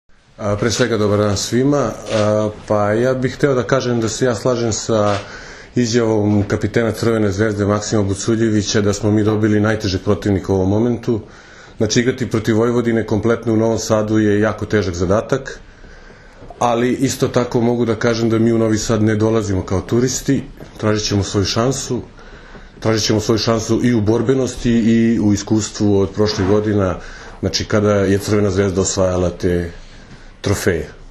U prostorijama Odbojkaškog saveza Srbije danas je održana konferencija za novinare povodom Finalnog turnira jubilarnog, 50. Kupa Srbije.
IZJAVA